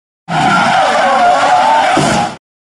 Small Car Crash Sound Effect Free Download
Small Car Crash